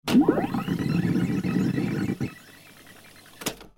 دانلود صدای تلویزیون 1 از ساعد نیوز با لینک مستقیم و کیفیت بالا
جلوه های صوتی